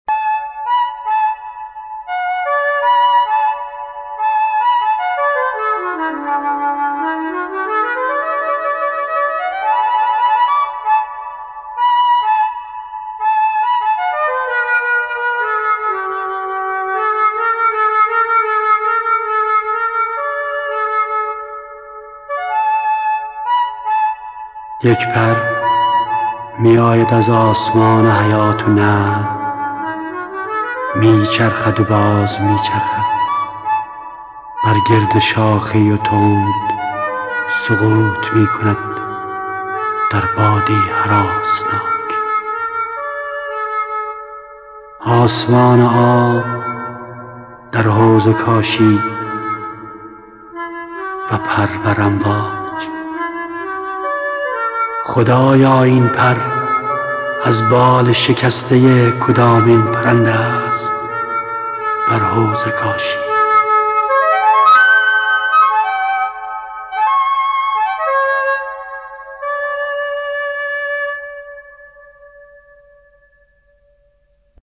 گوینده :   [احمدرضا احمدی]
آهنگساز :   آلبرت آراکلیان